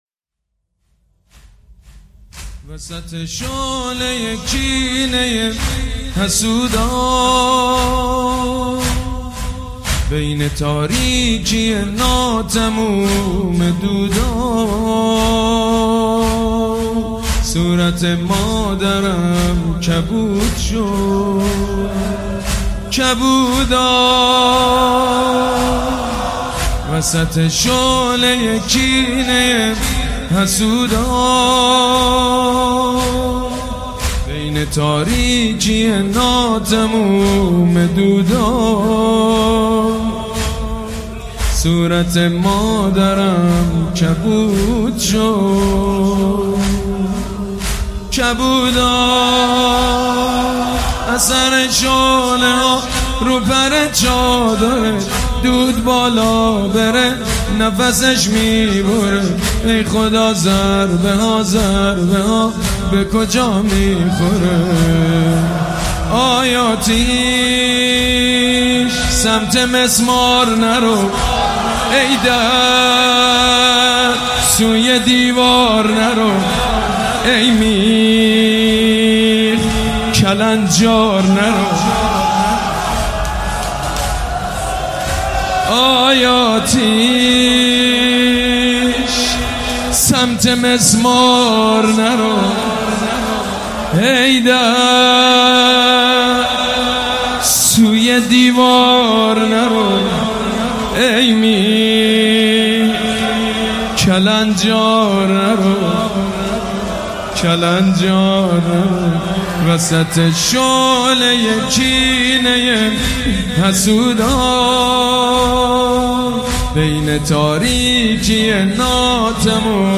مداحی جدید حاج سید مجید بنی فاطمه شب سوم ایام فاطمیه دوم دوشنبه 15 بهمن 1397 حسنیه ریحانة الحسین (ع) تهران